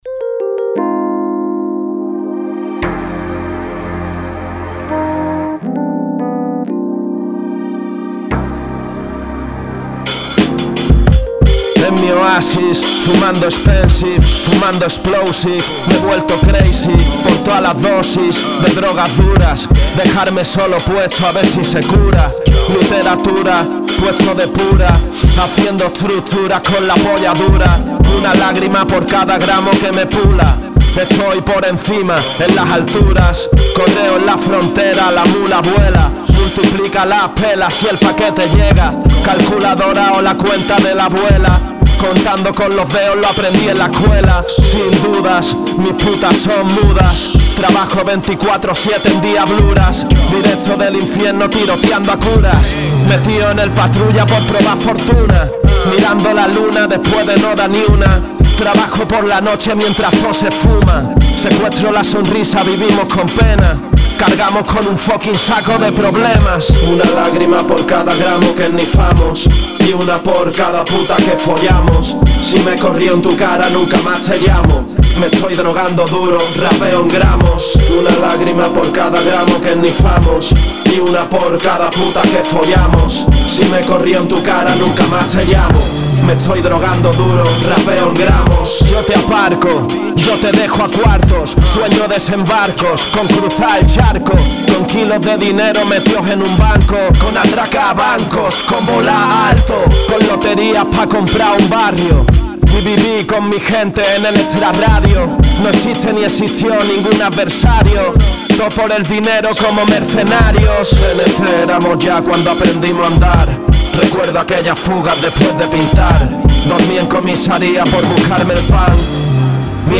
Está grabado, editado, mezclado y masterizado